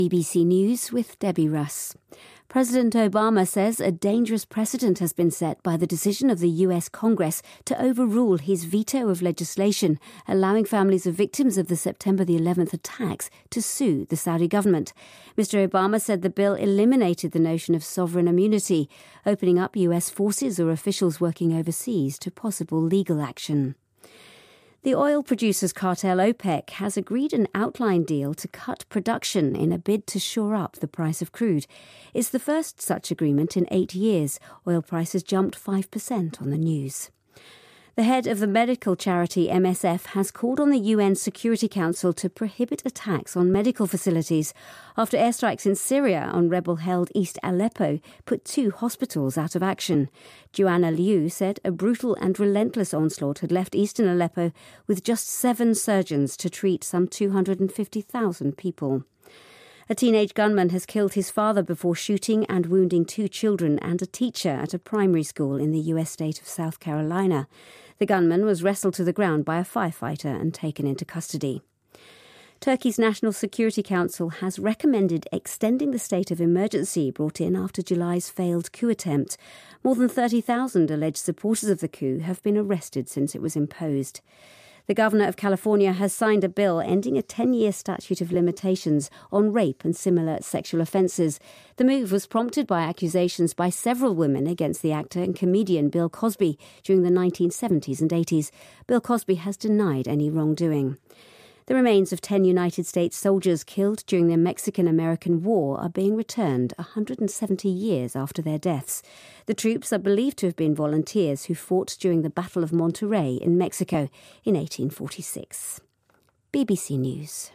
日期:2016-10-01来源:BBC新闻听力 编辑:给力英语BBC频道